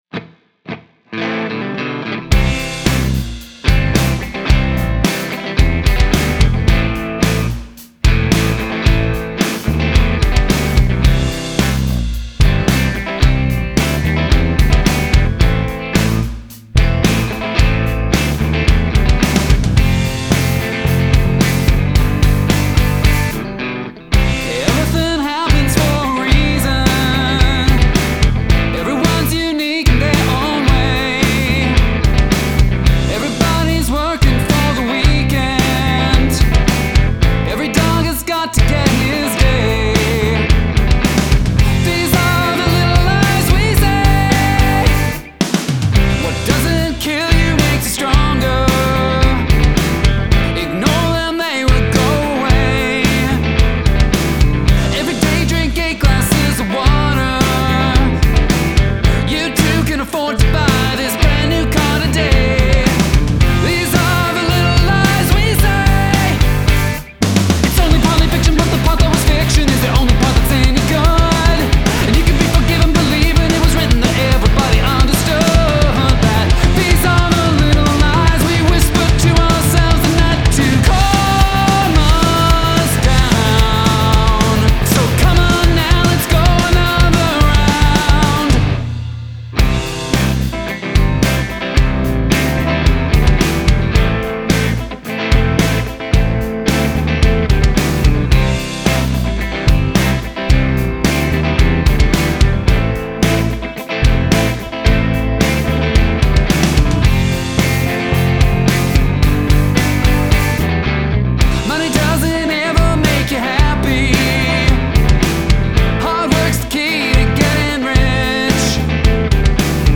This is super-catchy, and the vocals are great.